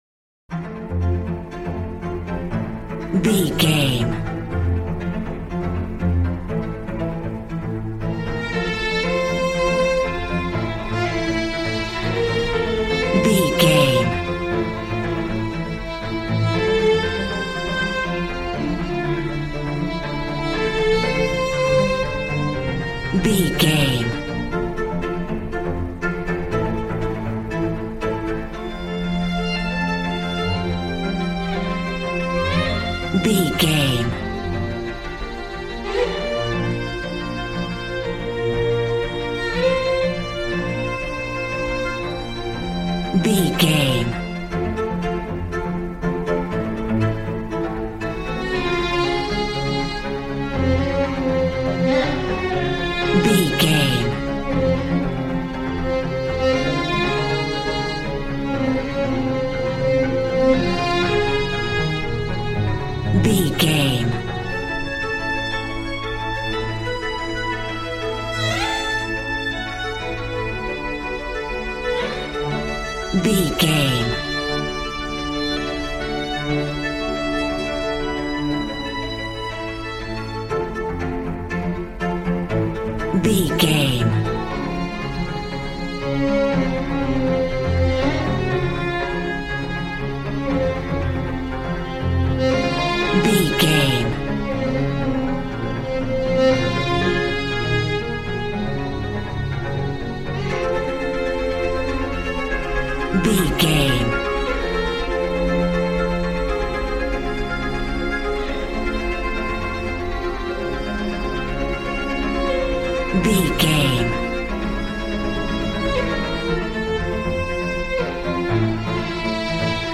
Modern film strings for romantic love themes.
Regal and romantic, a classy piece of classical music.
Aeolian/Minor
cello
violin
brass